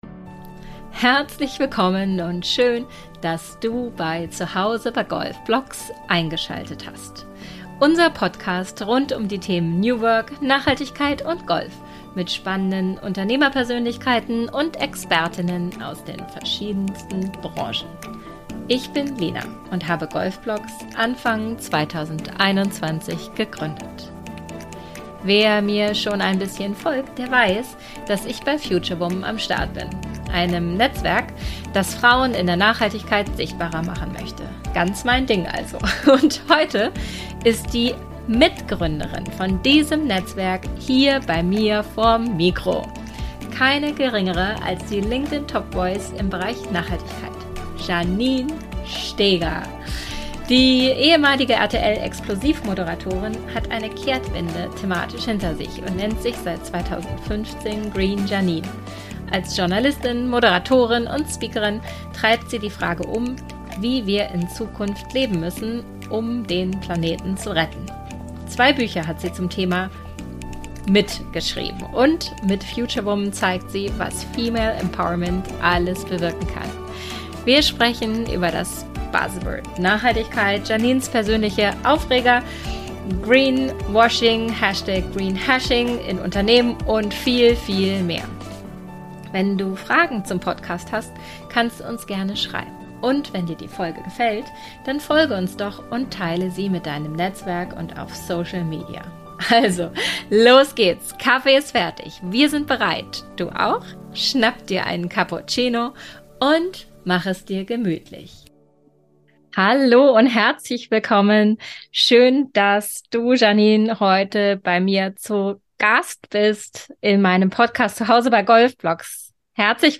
Future-Lifestyle geht nur nachhaltig. Zwei Futurewoman im Gespräch